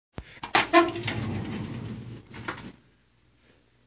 Filing cabinet open 2